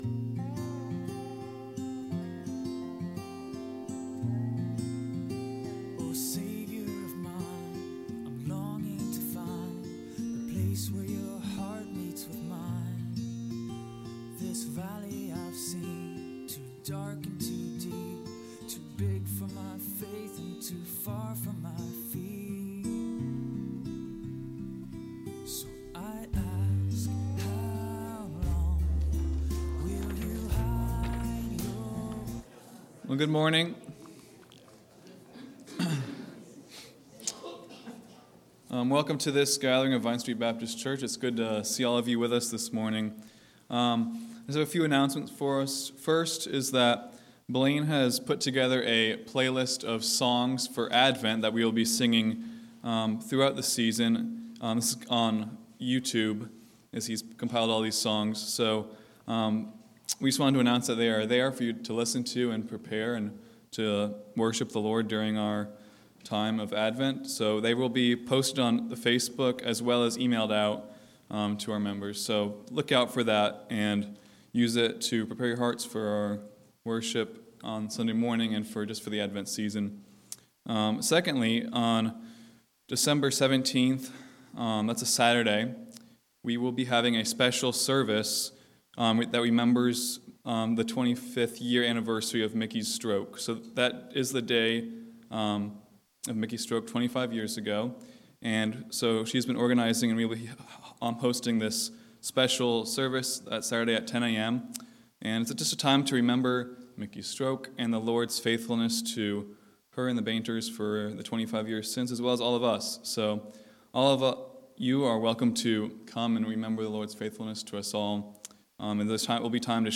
November 20 Worship Audio – Full Service